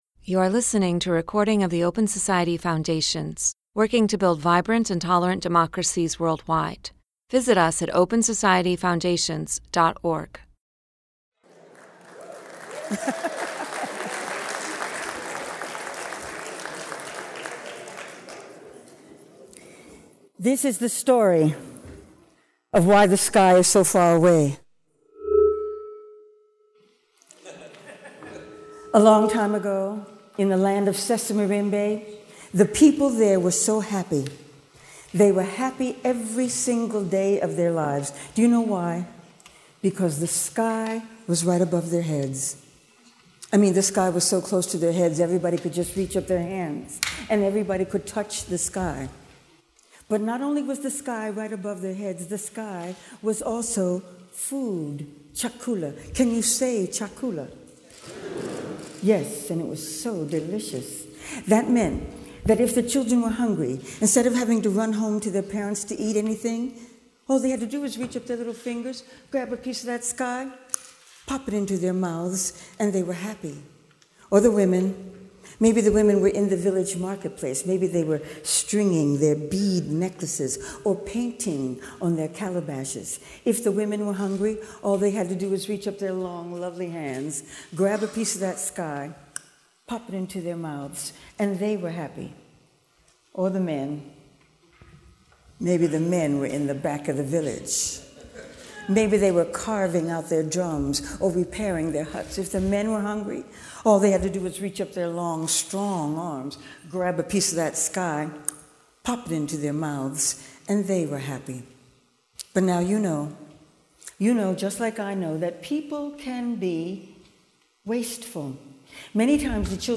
At its 20th-anniversary celebration, the Open Society Institute–Baltimore presented the final Justice for All awards and introduced the 2018 Community Fellows.